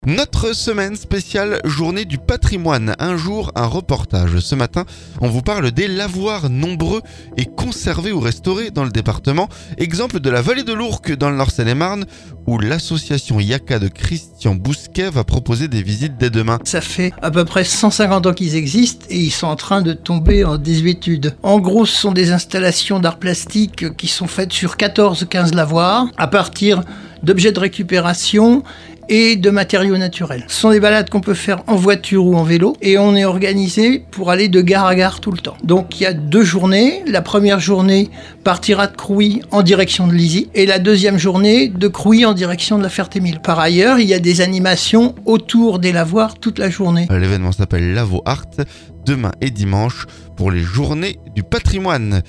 Un jour, un reportage. Ce vendredo, on vous parle des lavoirs, nombreux et conservés ou restaurés dans le département.